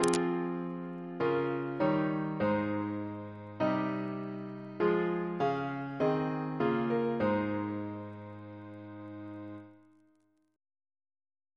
Single chant in A♭ Composer